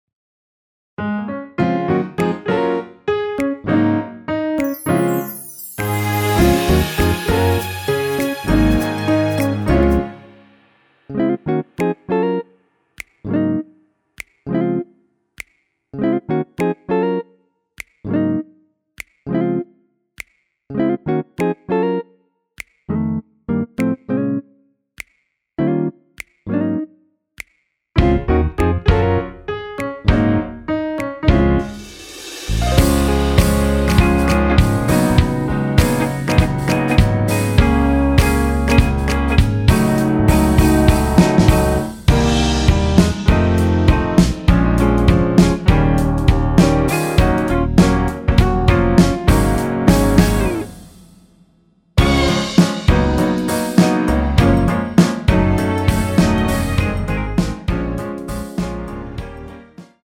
원키에서 (-2)내린 MR 입니다.
Db
앞부분30초, 뒷부분30초씩 편집해서 올려 드리고 있습니다.